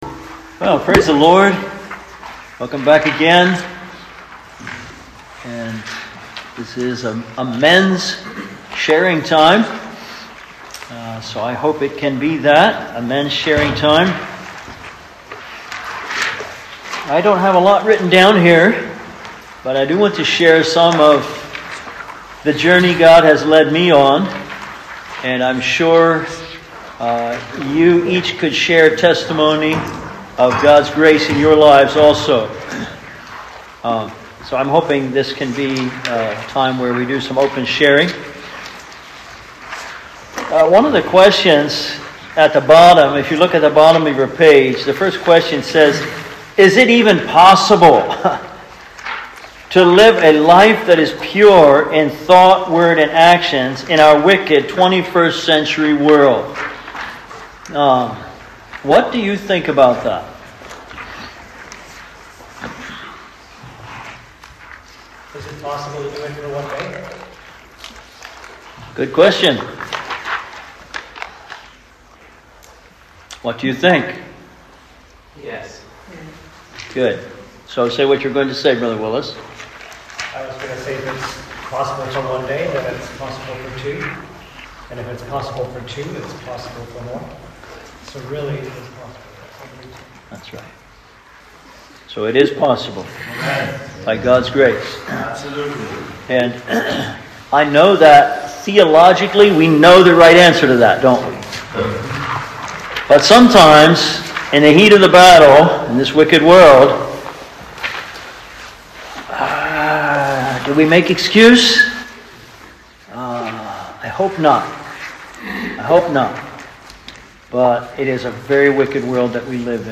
Service Type: Men's Seminar